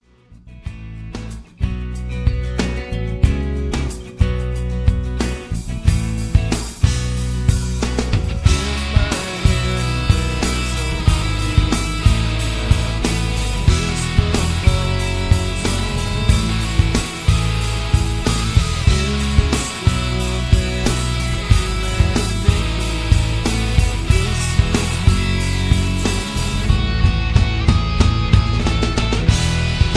(Key-C#m) Karaoke MP3 Backing Tracks
Just Plain & Simply "GREAT MUSIC" (No Lyrics).